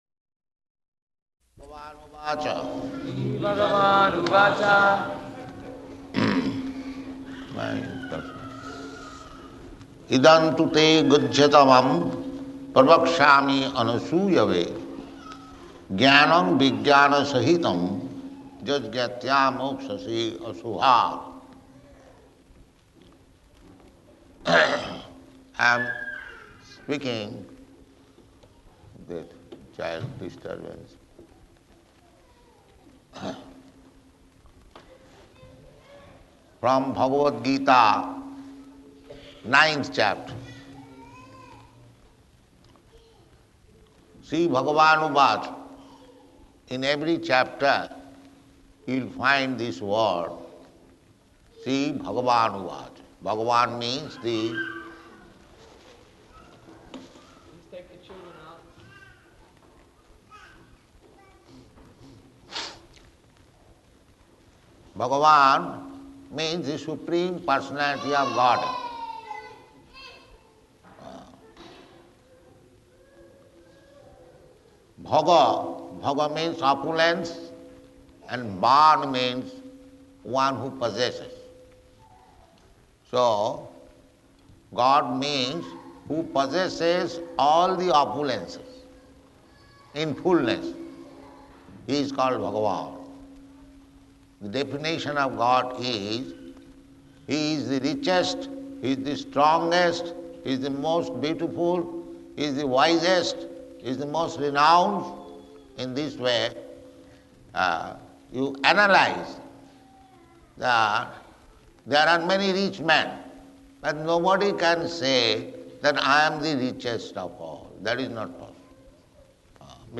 Bhagavad-gītā 9.1–2 --:-- --:-- Type: Bhagavad-gita Dated: June 30th 1974 Location: Melbourne Audio file: 740630BG.MEL.mp3 Prabhupāda: Śrī-bhagavān uvāca .
[children crying in background] Devotee: Please take the children out.